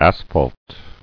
[as·phalt]